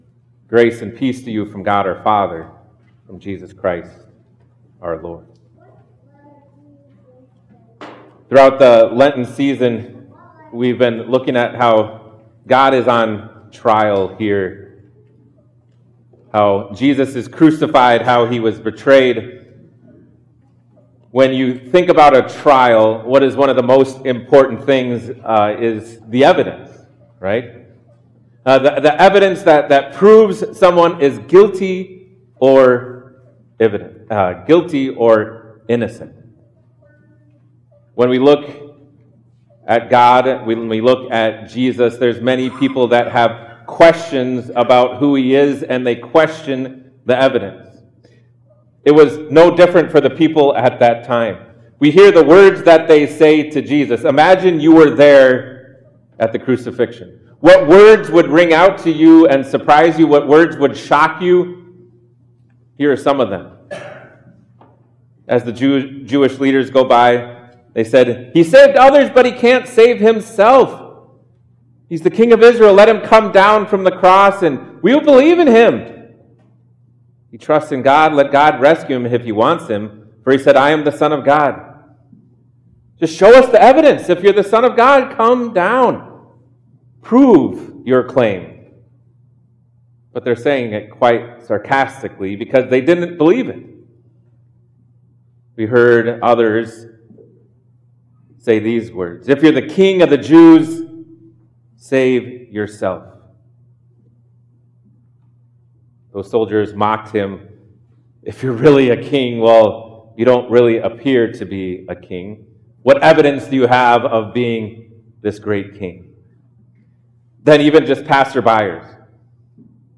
Series: God on Trial, Good Friday, Tenebrae, Triduum